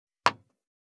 195,テーブル等に物を置く,食器,グラス,コップ,工具,小物,雑貨,コトン,トン,ゴト,ポン,ガシャン,ドスン,ストン,カチ,タン,バタン,スッ,
効果音物を置く